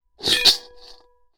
Metal_61.wav